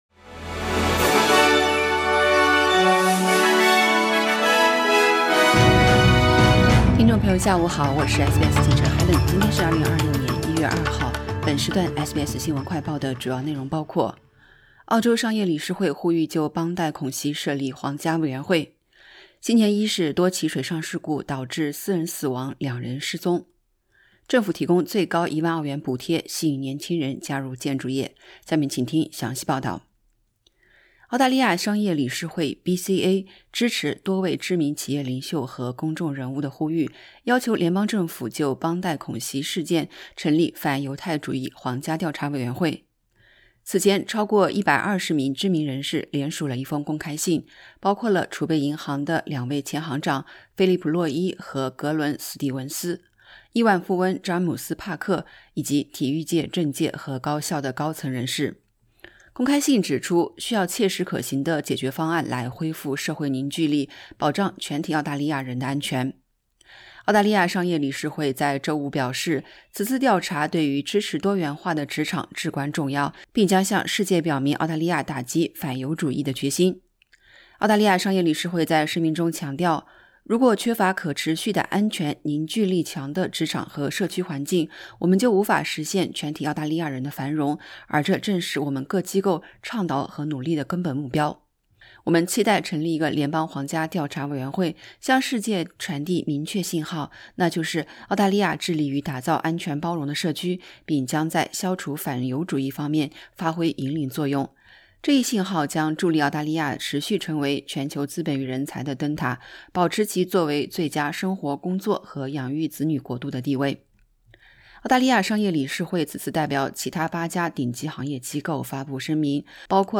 【SBS 新闻快报】澳洲商业理事会呼吁就邦迪恐袭设立皇家委员会 04:32 As questions and claims swirl amid increasing pressure for a national royal commission, experts weigh in on the state and federal government's existing plans.